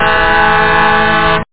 TruckHorn.mp3